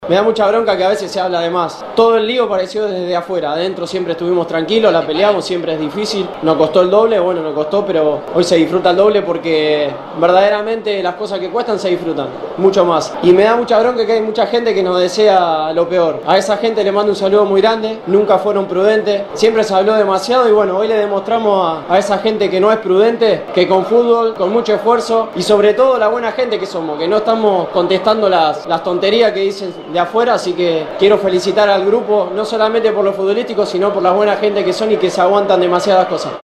PABLO PEREZ TRAS LA VICTORIA Y CLASIFICACIÓN: